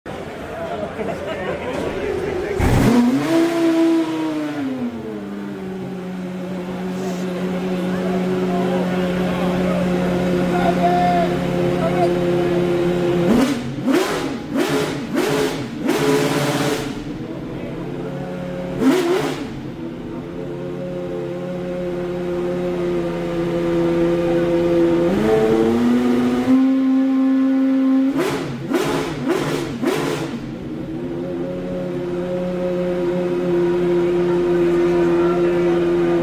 McLaren Solus GT Startup, Revs Sound Effects Free Download